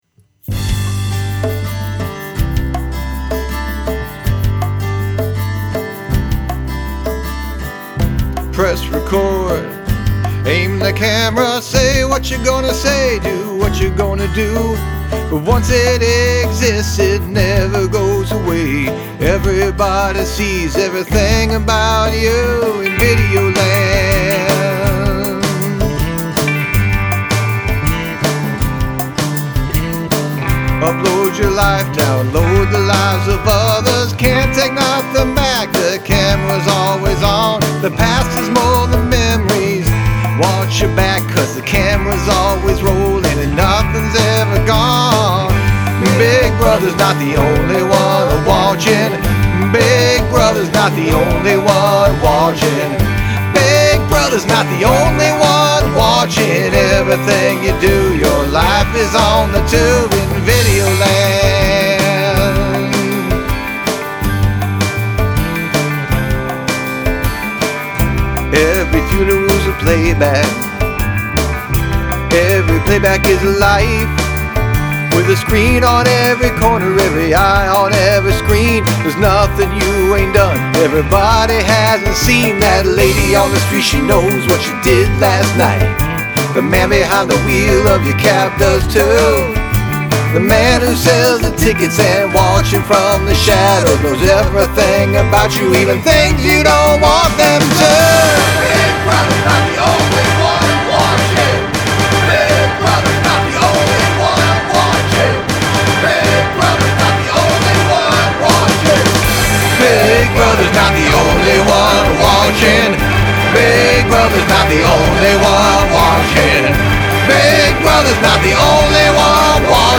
Must include a classical music sample
Musically, I like your percussion a lot.